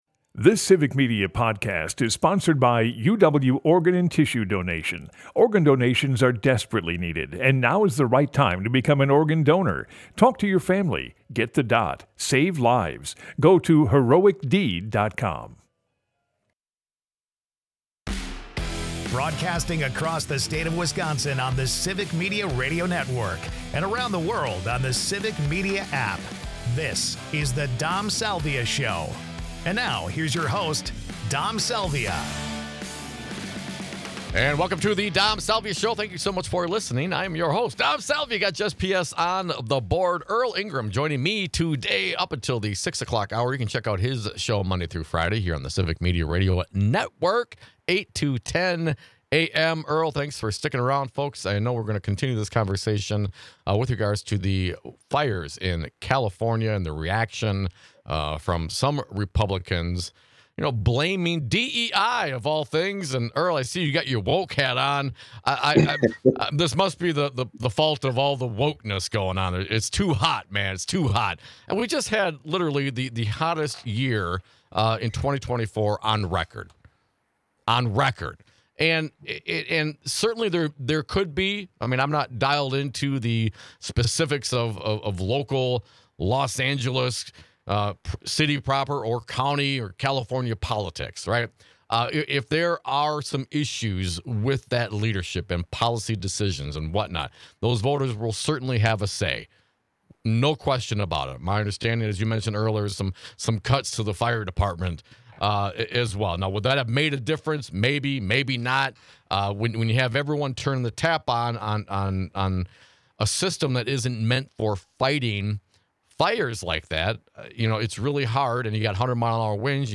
Callers weigh in on their views for what's happening, why, and what's to come.